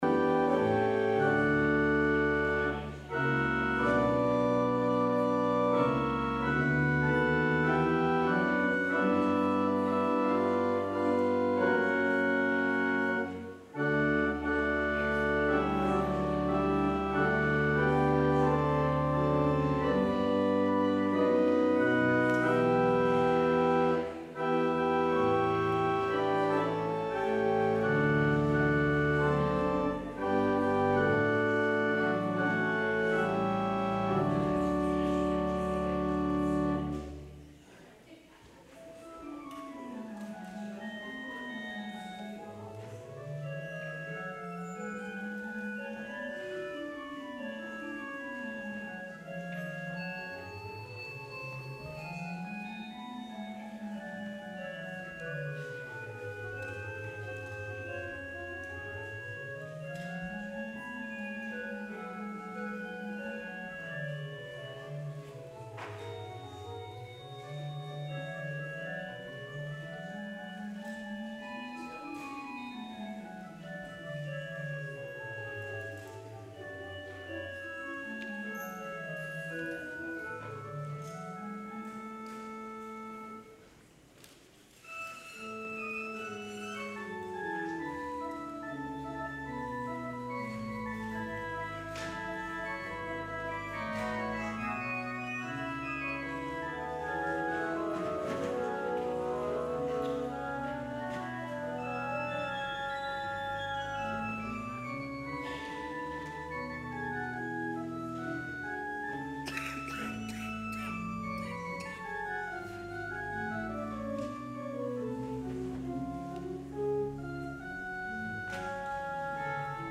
Traditional Sermon